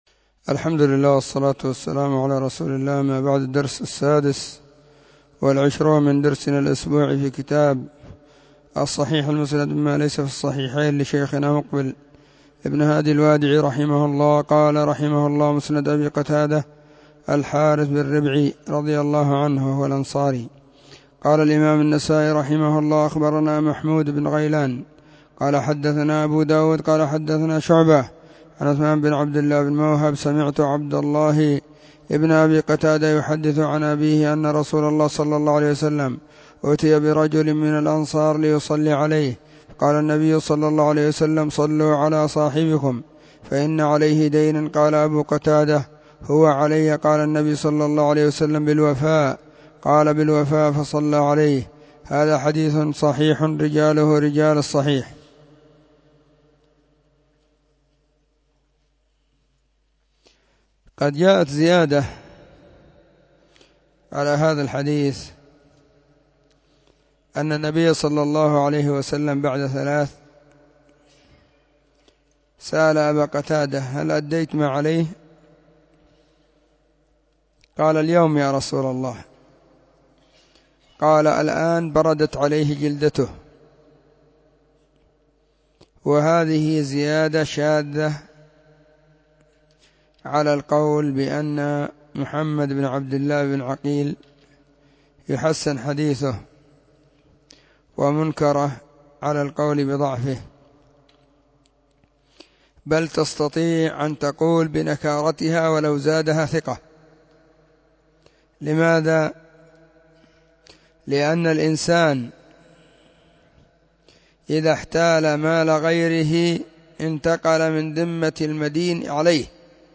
خميس -} 📢مسجد الصحابة – بالغيضة – المهرة، اليمن حرسها الله.